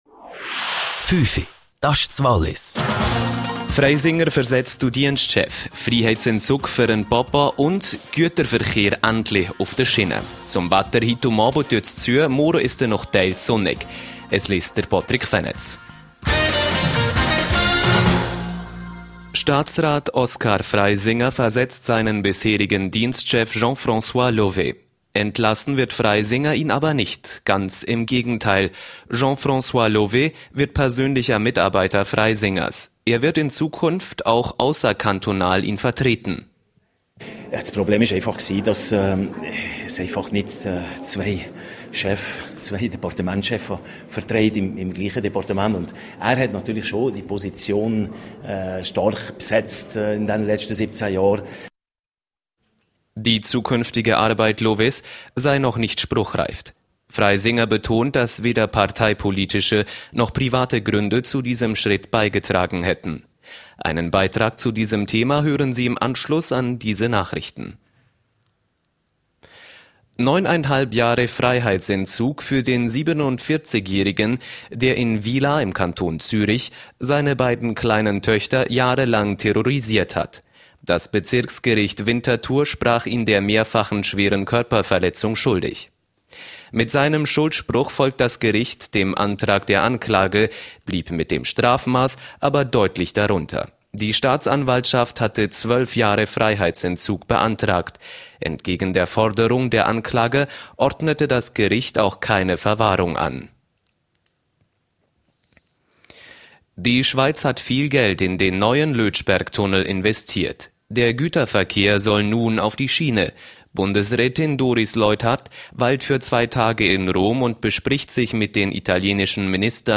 17:00 Uhr Nachrichten (5.89MB)